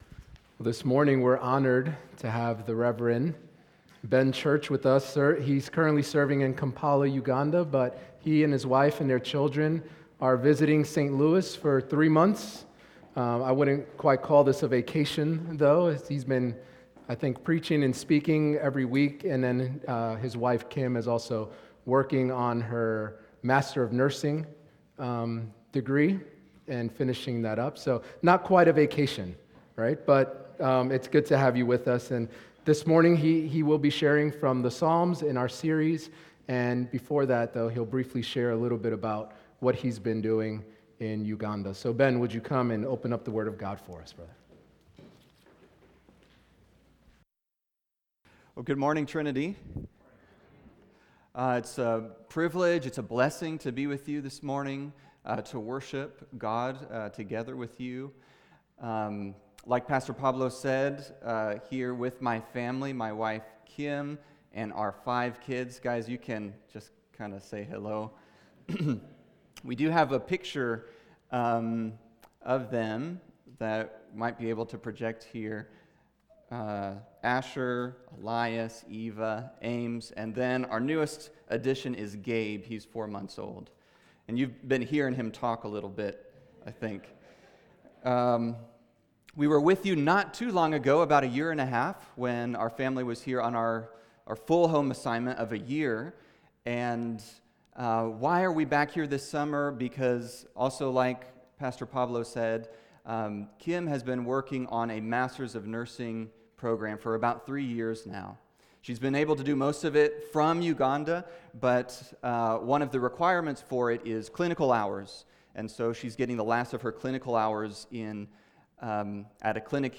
Passage: Psalm 5 Service Type: Weekly Sunday